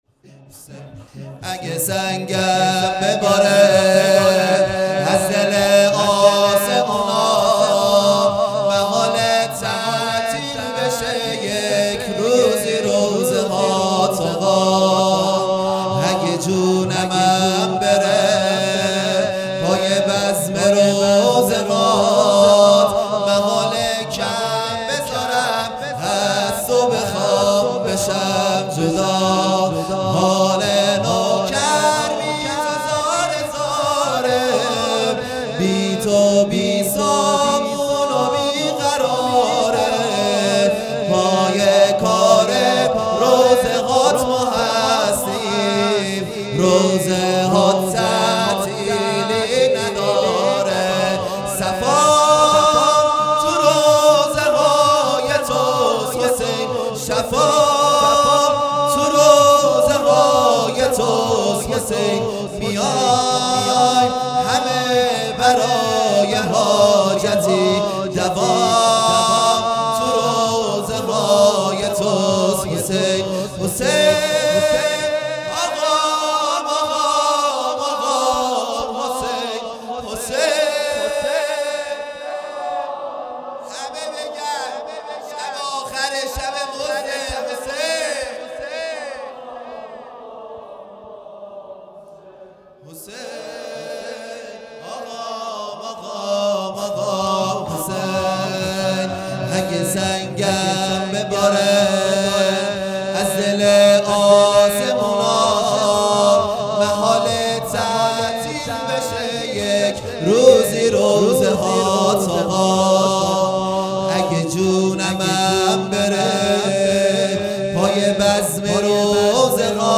خیمه گاه - هیئت بچه های فاطمه (س) - شور سوم | اگه سنگم بباره
دهه اول محرم الحرام ۱۴۴٢ | شام غریبان